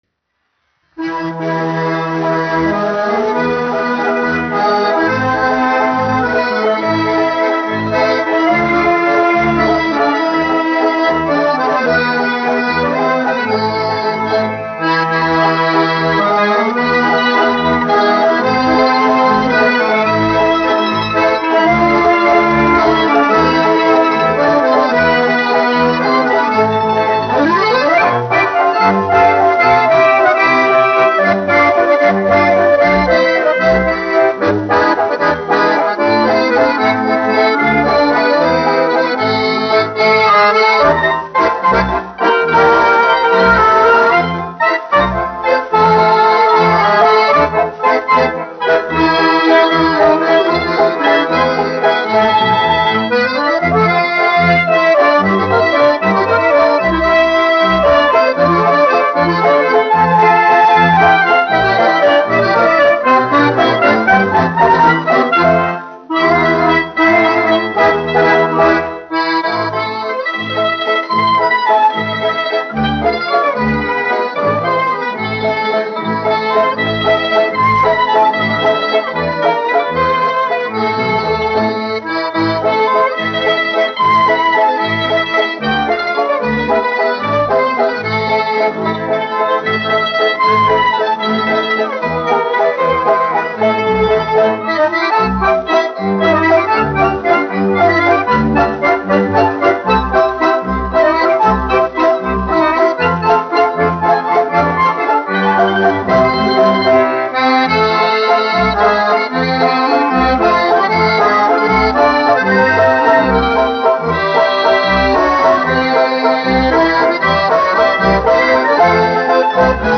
1 skpl. : analogs, 78 apgr/min, mono ; 25 cm
Valši
Populārā instrumentālā mūzika
Latvijas vēsturiskie šellaka skaņuplašu ieraksti (Kolekcija)